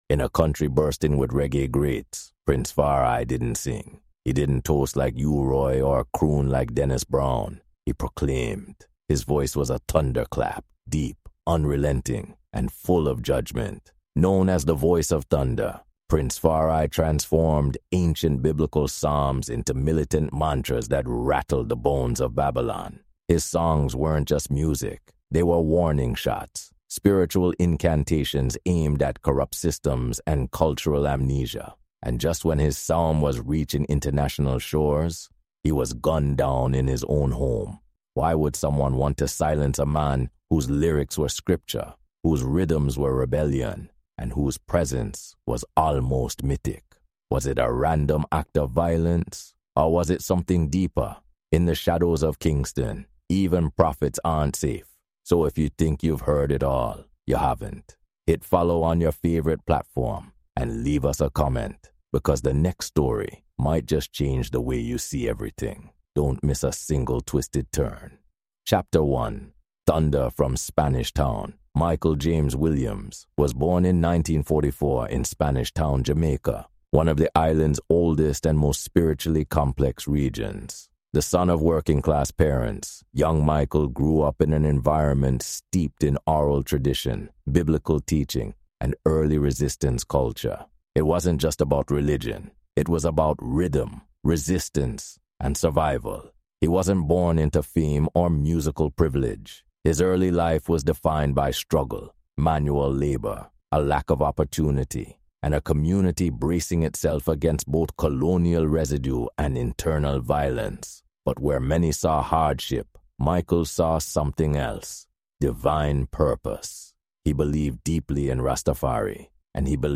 Journey with our Caribbean podcast team into Kingston’s underbelly, tracing Far I’s roots from colonization‑scarred landscapes to international sound systems. Hear eyewitness accounts, studio out‑takes, and newly uncovered police files that question the offi